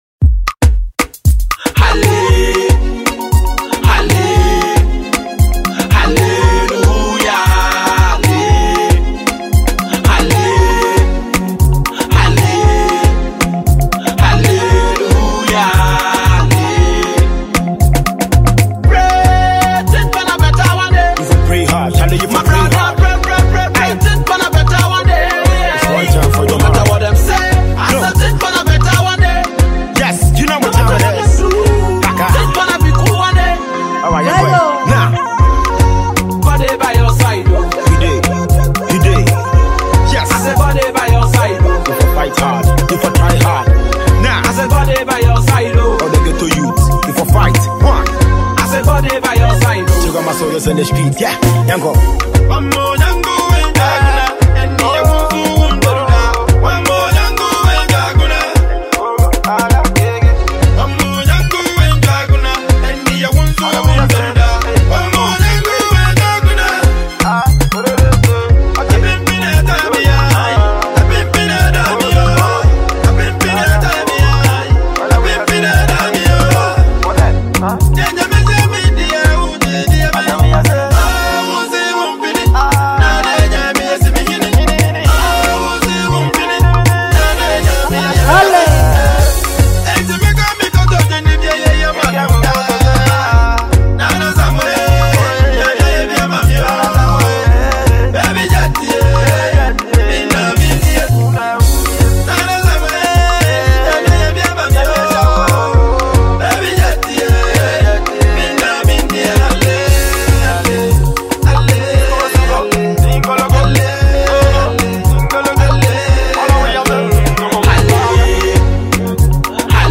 Melodious voice